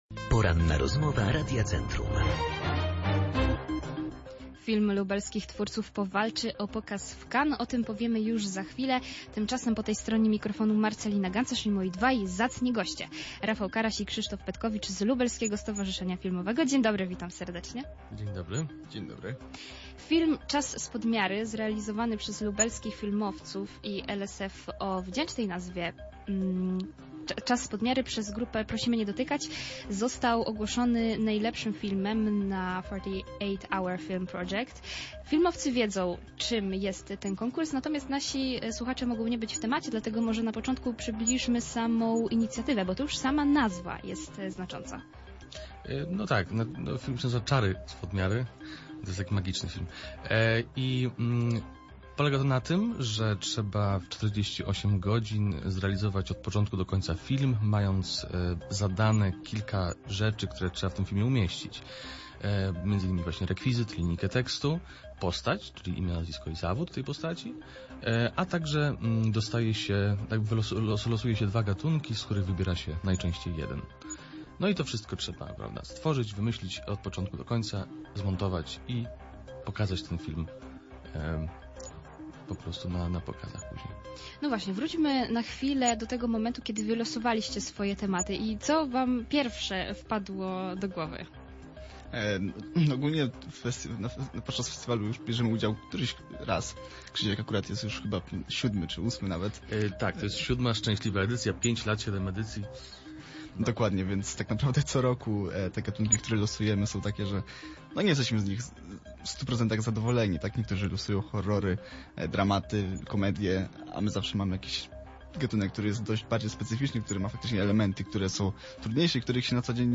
Całą rozmowę z twórcami tej produkcji znajdziecie poniżej.